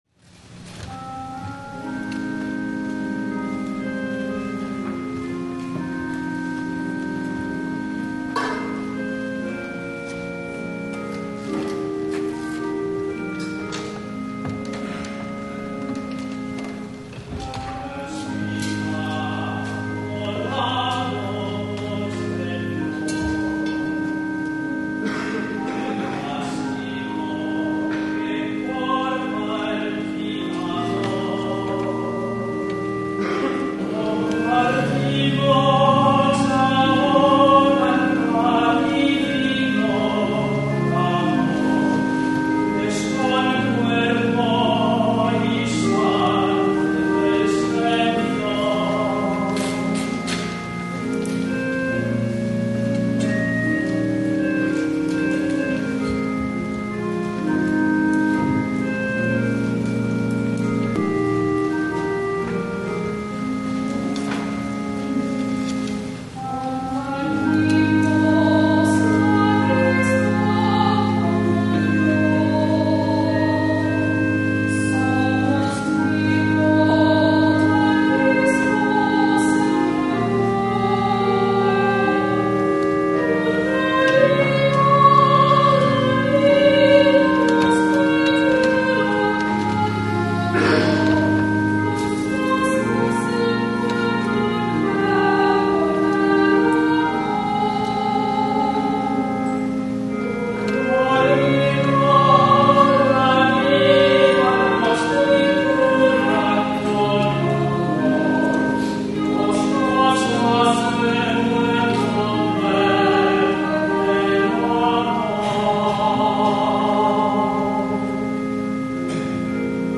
WORLD COMMUNION SUNDAY
THE OFFERTORY